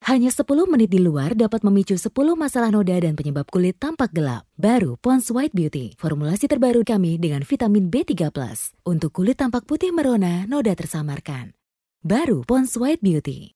Voice Samples
female